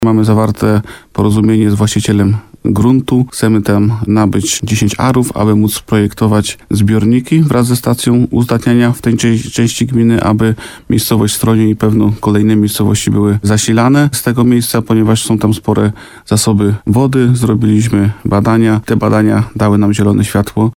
W gminie Łukowica powstaną nowe zbiorniki wody pitnej. Ma to zabezpieczyć mieszkańców na czas suszy - mówi wójt Bogdan Łuczkowski.